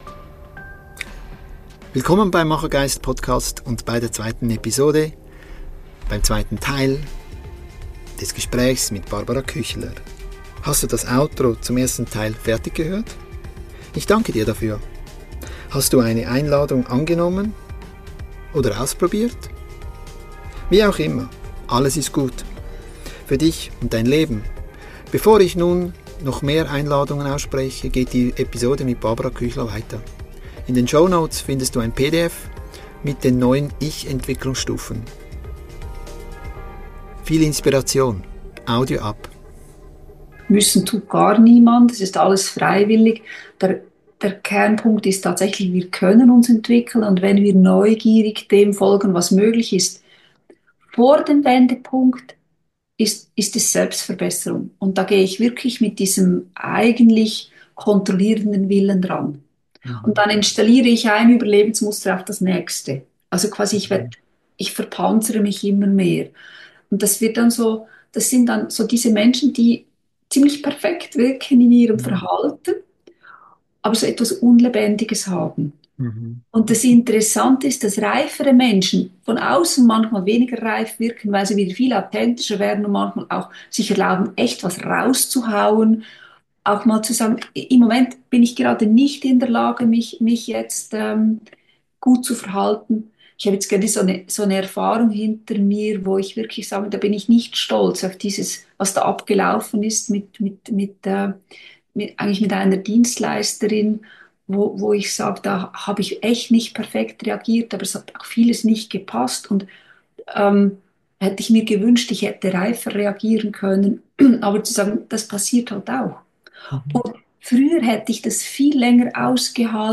Die Intro's und Outro's führen dich durch das gesamte Gespräch.